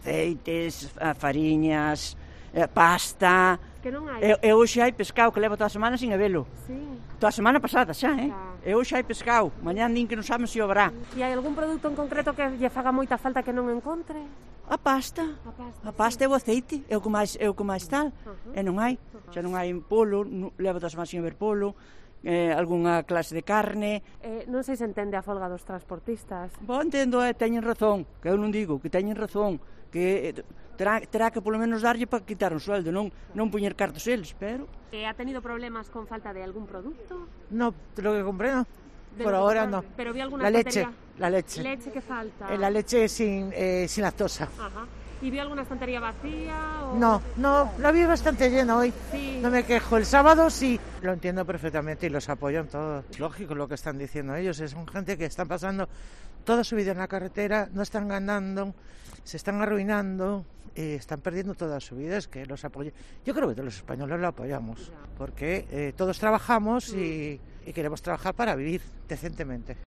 Varias clientas nos cuentan qué productos echan en falta